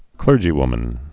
(klûrjē-wmən)